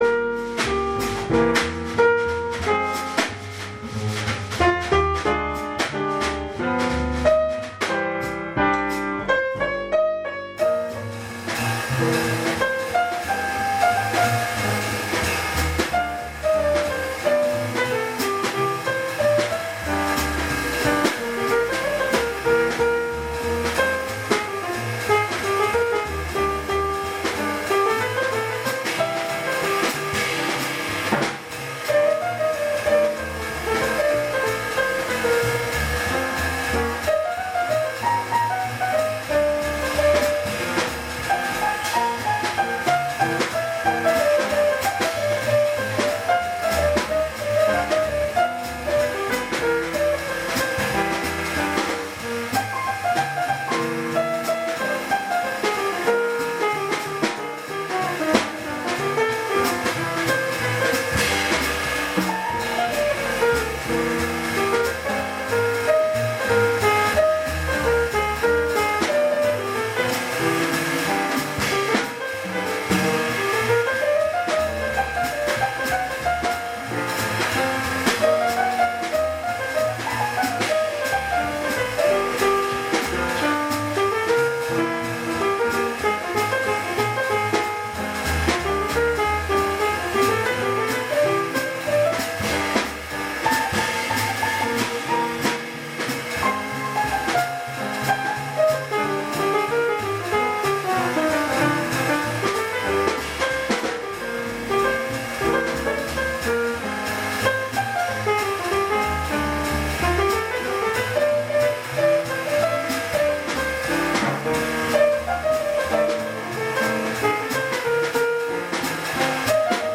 Be Bop〜60年代あたりの４ビートでSwingする 'Real Jazz Piano' を目指しています
Sample music（Live録音です　　会議用で録音したので音質悪いですが）